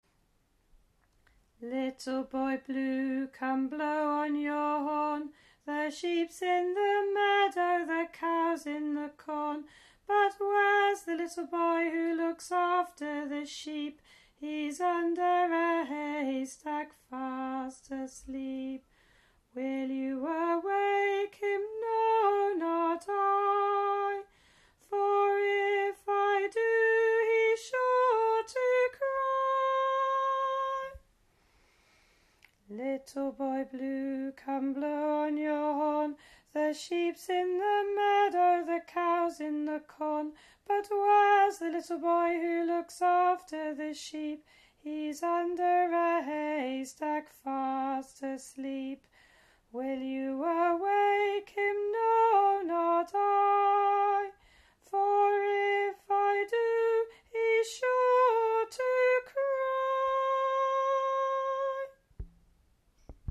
The tune is different from the one heard on many of the lullaby websites.
file details Lullaby recording 2025-03-03 Público Baixar